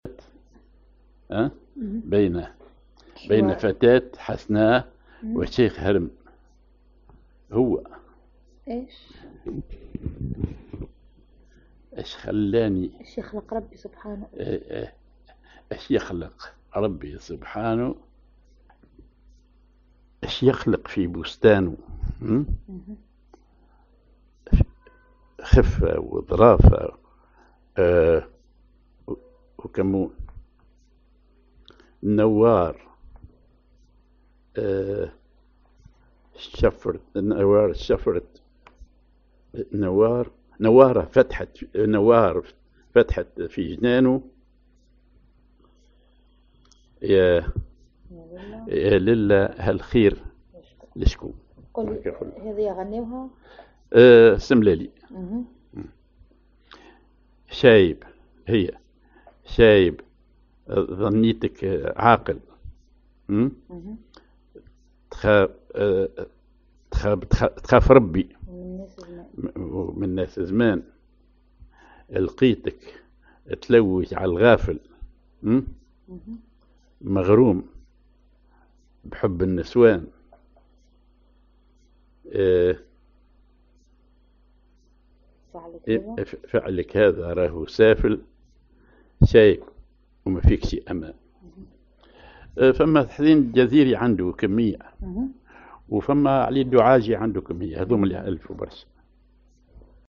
ar بياتي
أغنية